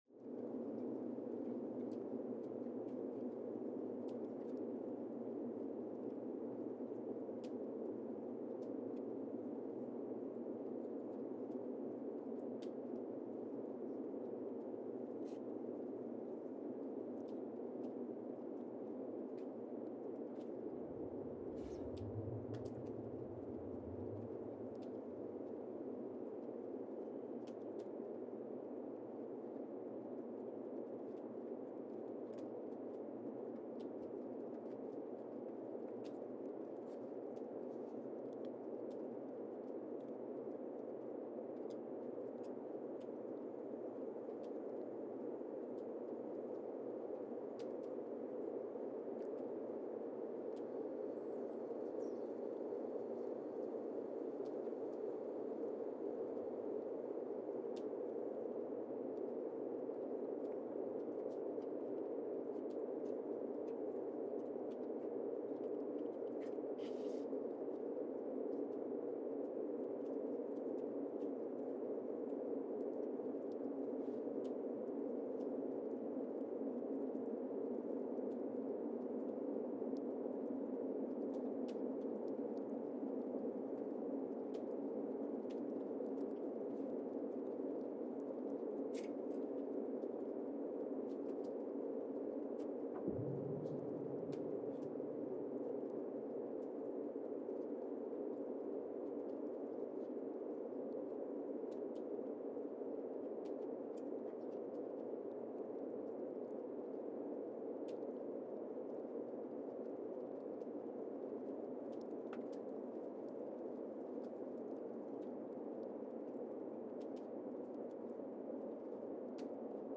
Casey, Antarctica (seismic) archived on April 29, 2025
Station : CASY (network: GSN) at Casey, Antarctica
Sensor : Streckheisen STS-1VBB
Recorder : Quanterra Q330 @ 20 Hz
Speedup : ×1,800 (transposed up about 11 octaves)
Loop duration (audio) : 05:36 (stereo)
Gain correction : 25dB
SoX post-processing : highpass -2 90 highpass -2 90